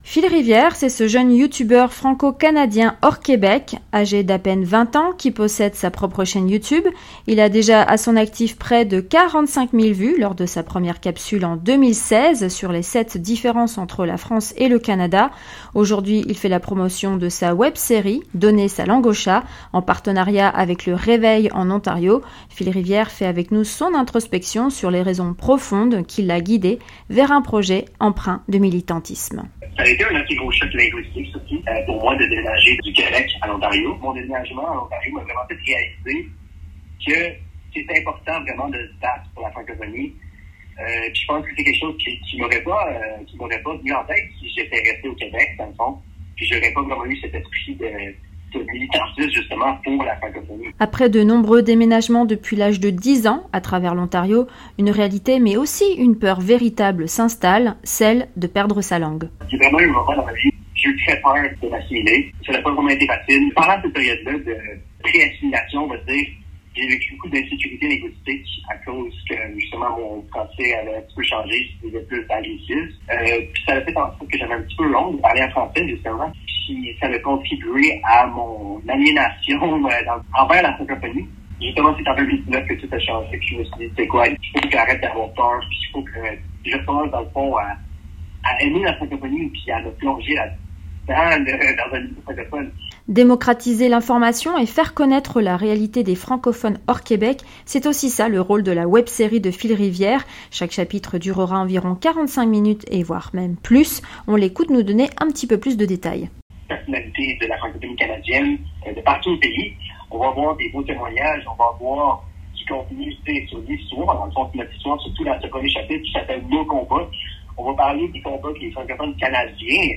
Il lance sa web-série et nous parle avec ferveur de son amour de la francophonie.